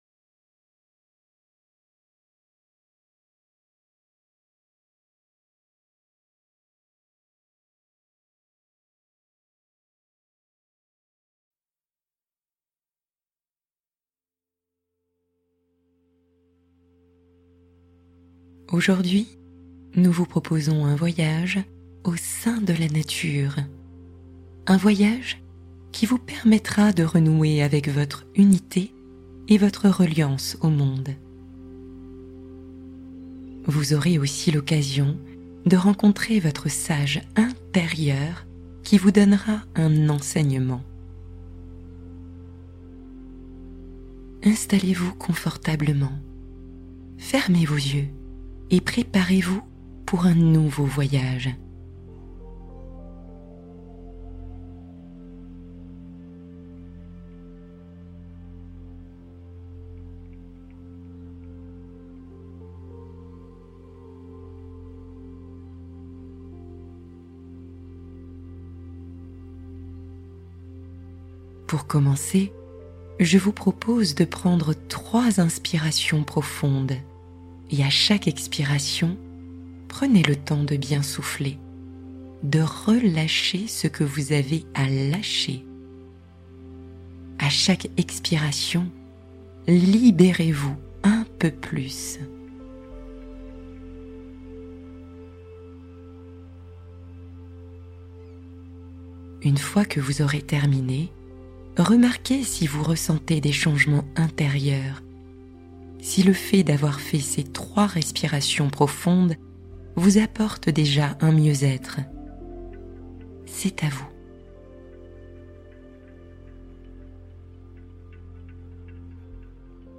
Rencontrez le Sage qui détient la réponse que vous cherchez | Méditation guidée profonde